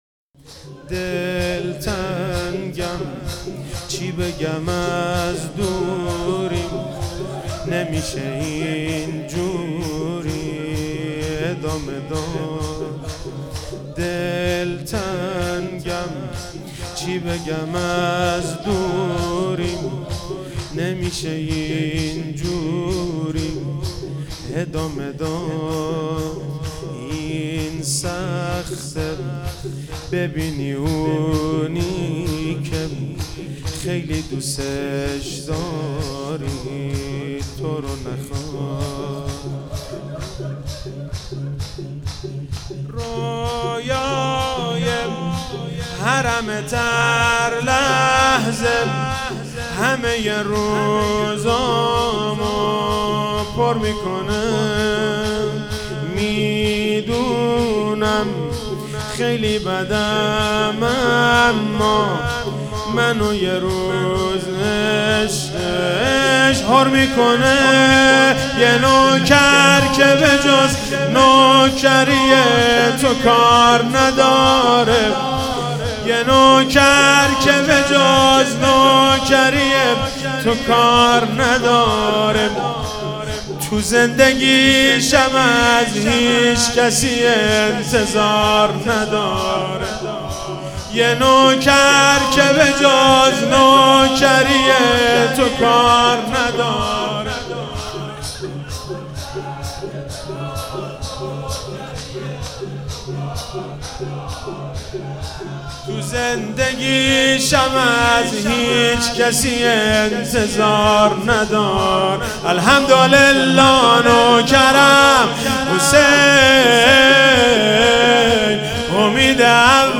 محرم99 - شب ششم - شور - دلتنگم چی بگم از دوری